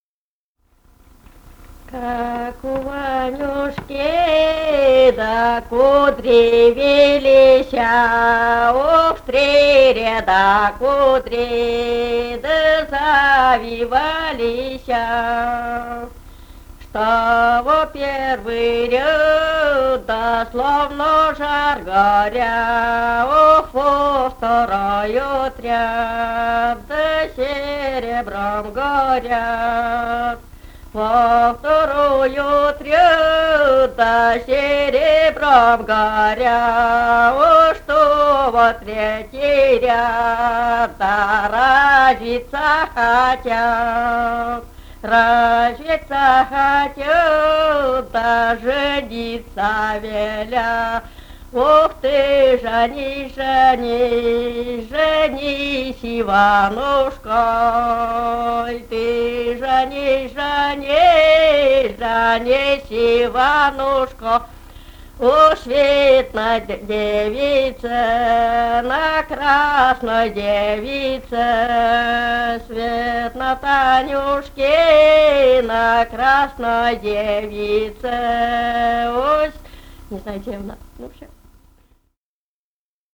Этномузыкологические исследования и полевые материалы
Пермский край, д. Пепеляево Очёрского района, 1968 г. И1077-16